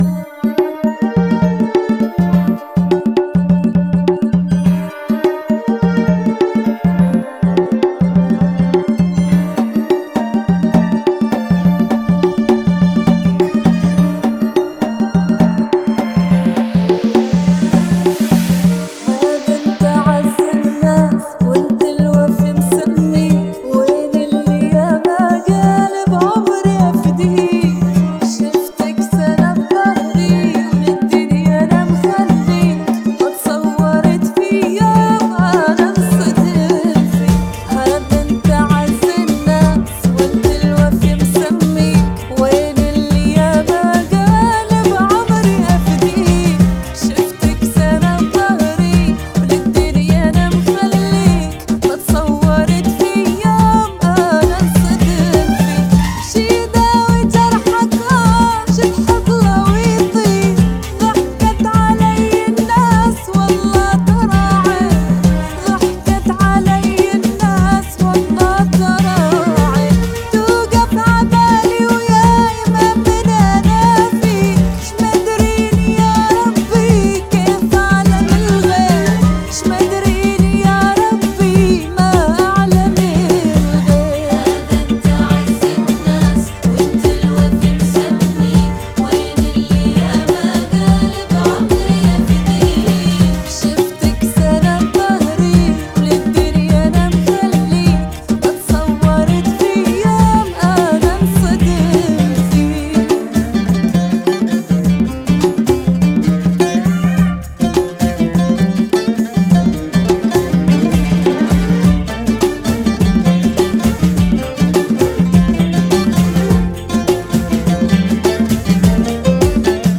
Funky [ 103 Bpm ]